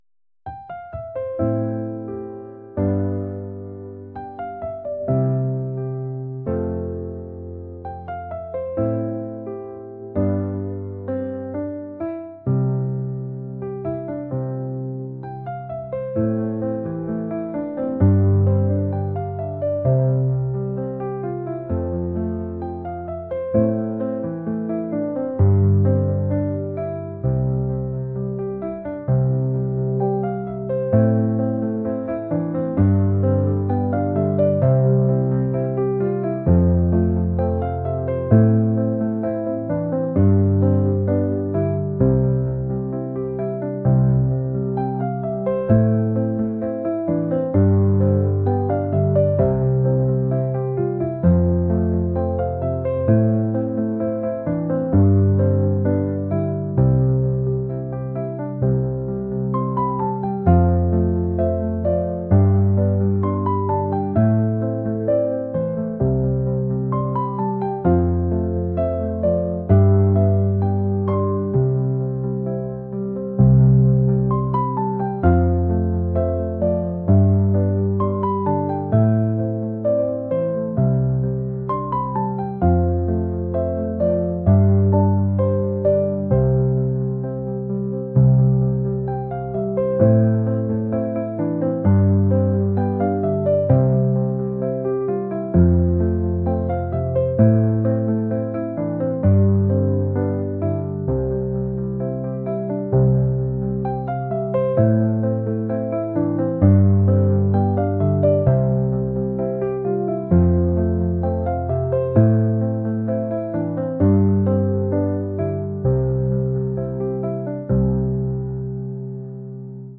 pop | acoustic | cinematic